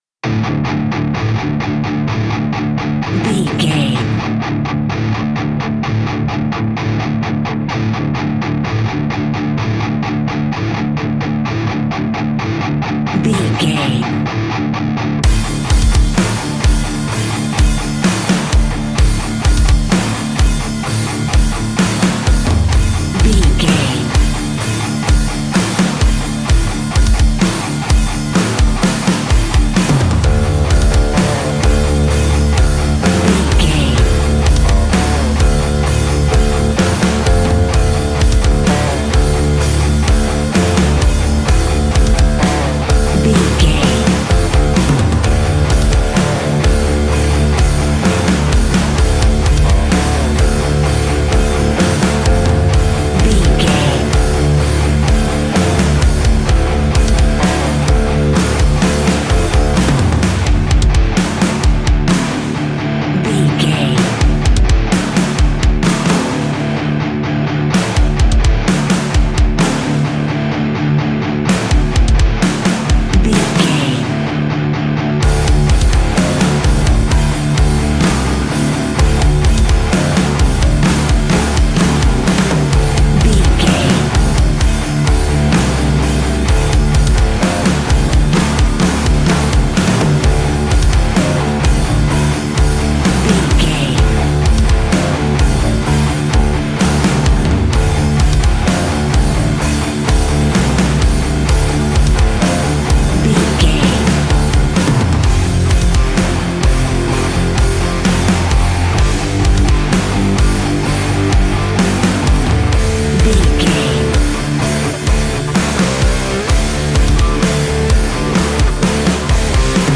Ionian/Major
D
hard rock
heavy metal
instrumentals